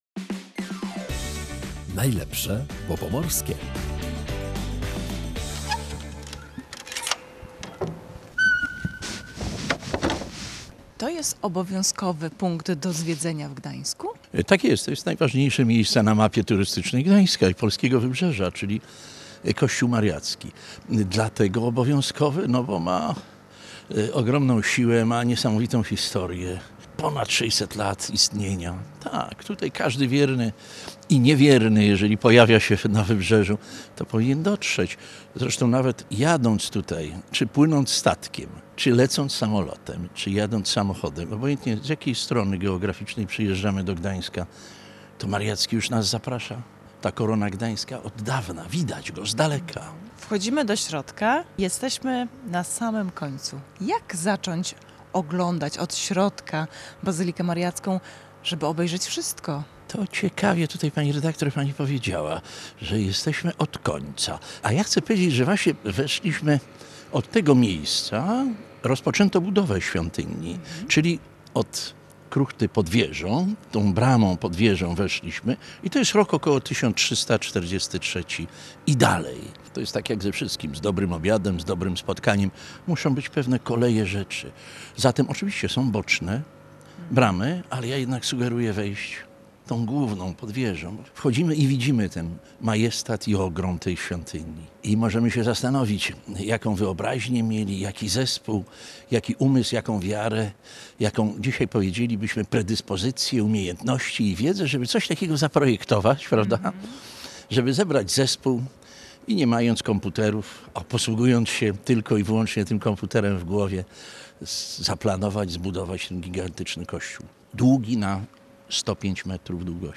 W audycji „Najlepsze, bo pomorskie” wybraliśmy się do Bazyliki Mariackiej – często nazywanej „Koroną Gdańska”.